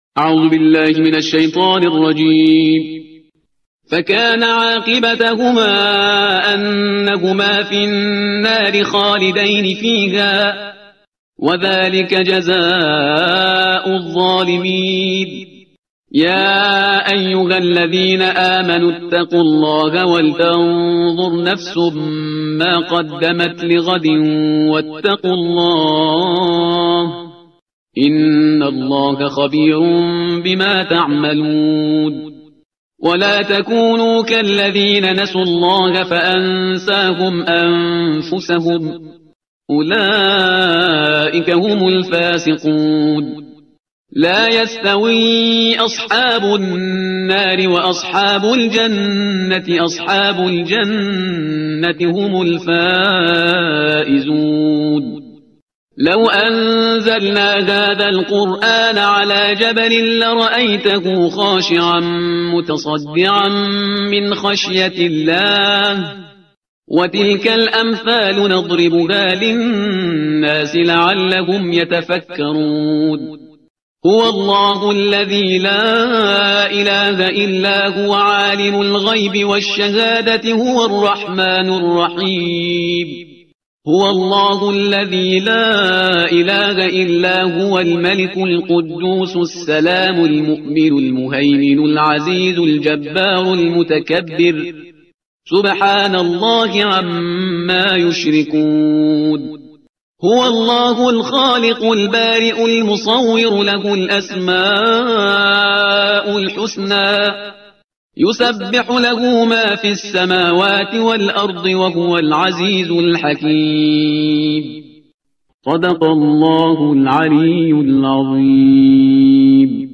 ترتیل صفحه 548 قرآن